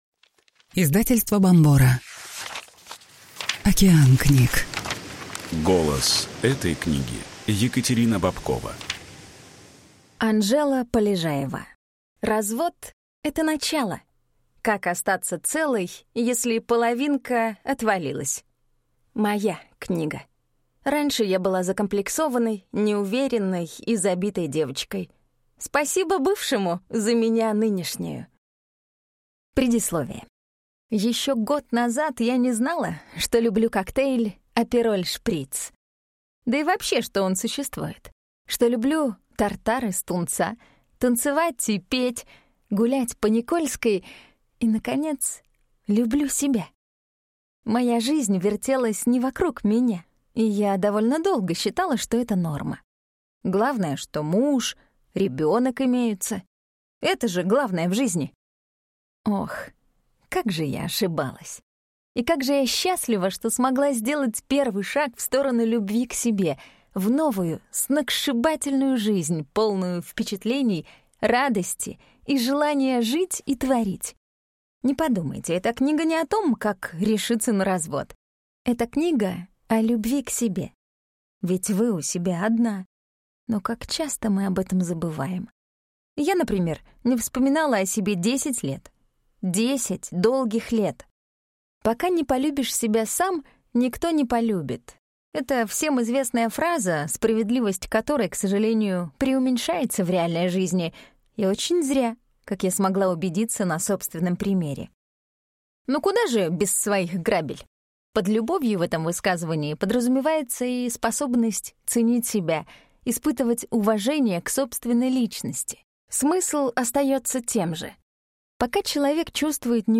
Аудиокнига Развод – это начало. Как остаться целой, если половинка отвалилась | Библиотека аудиокниг